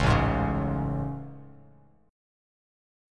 AM7 Chord
Listen to AM7 strummed